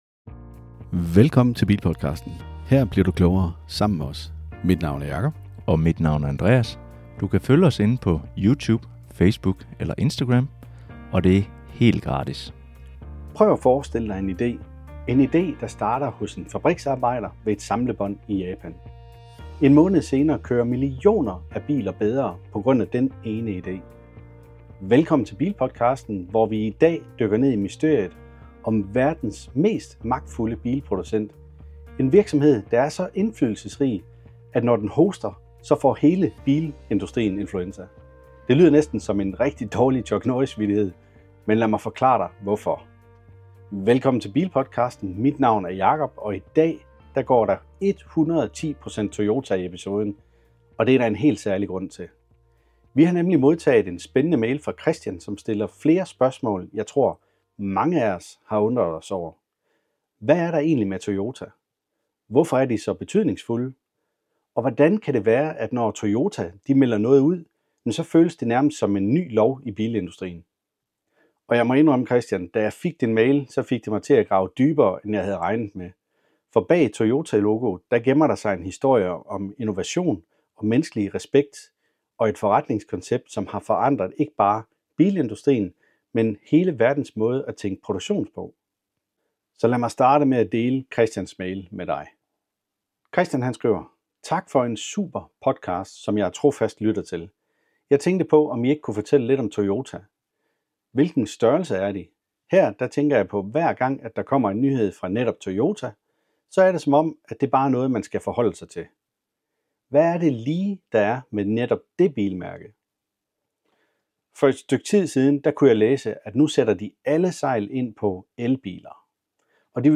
I bilpodcasten fortælles om fremtiden for danske bilejere, med fokus på både grøn energi, udfordringer med strøm, biltests, relevante nyheder og hvad man skal være opmærksom på som ny elbils ejer. Vi forsøger altid, at gøre dig og os selv klogere med højt humør og en god stemning.